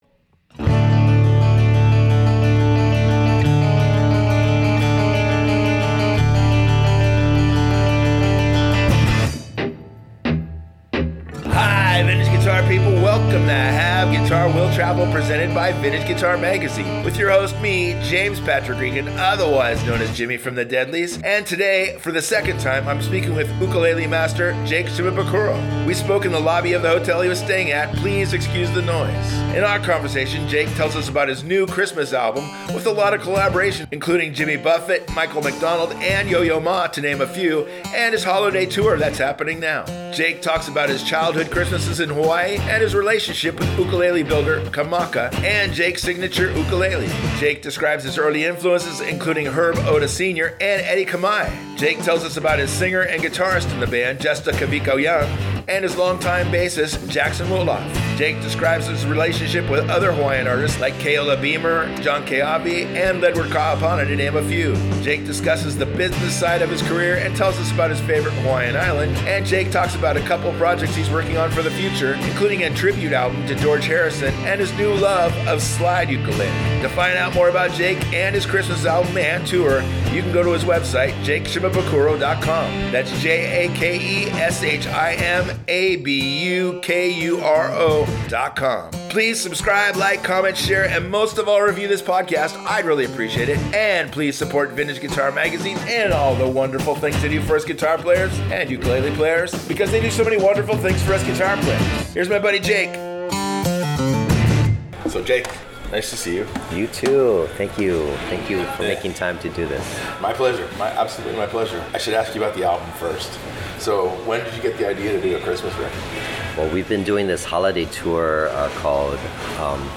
They spoke in the lobby of the hotel he was staying at, please excuse the noise.